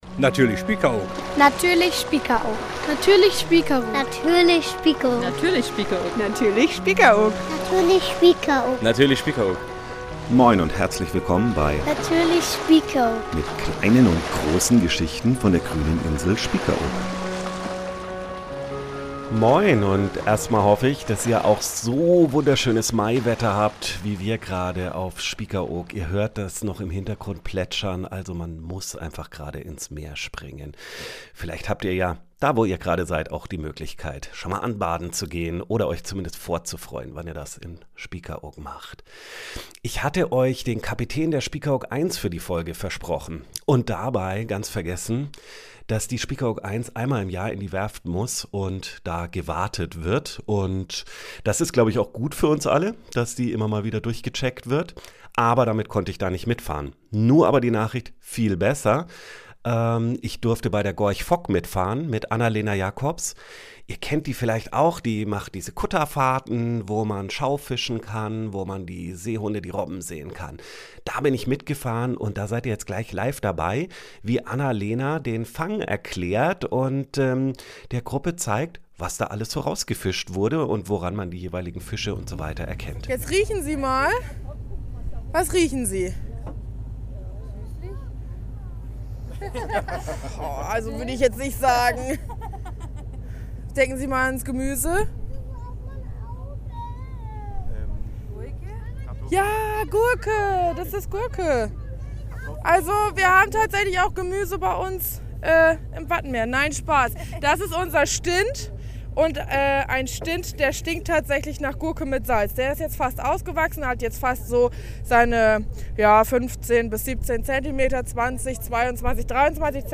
Wir sind mit an Bord bei einer Tour ab Spiekeroog und erleben eine besondere Vorführung: das Schaufischen .